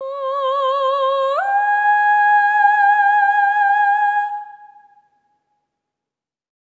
SOP5TH C#5-L.wav